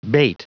Prononciation du mot bate en anglais (fichier audio)
Prononciation du mot : bate